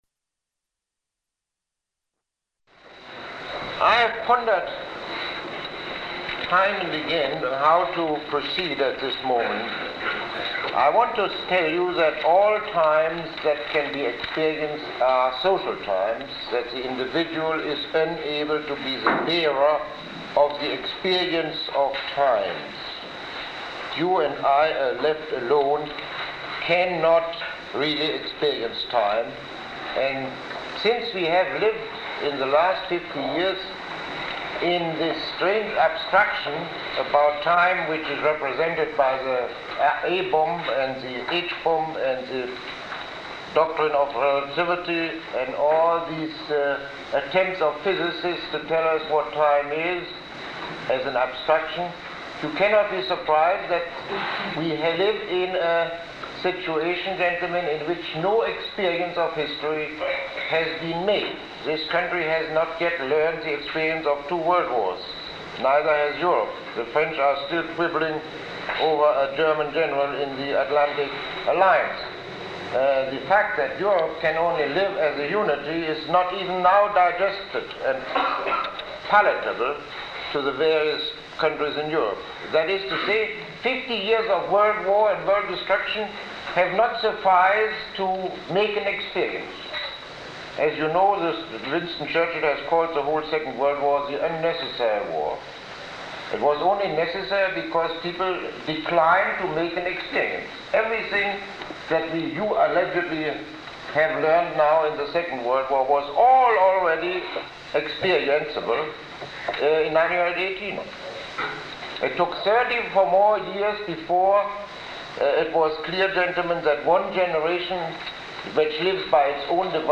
Lecture 08